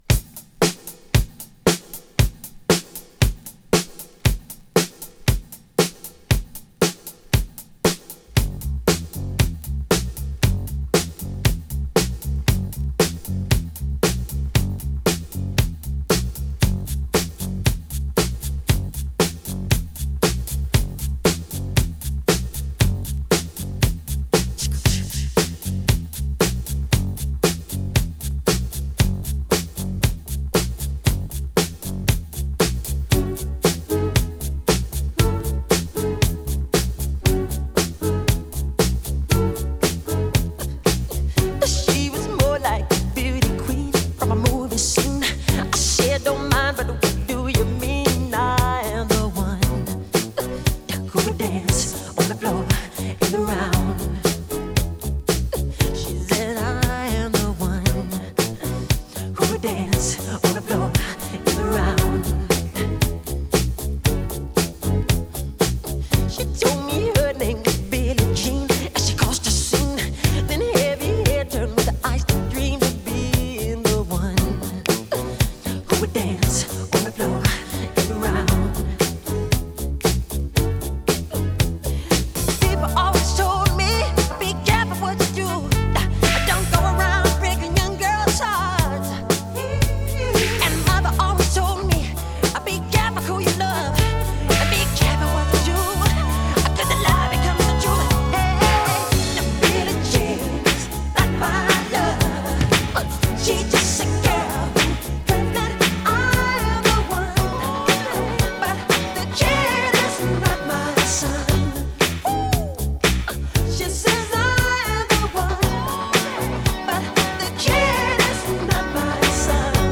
Funk / Soul、Disco
播放设备：SONY PS-X800 拾音头：SHURE V15VxMR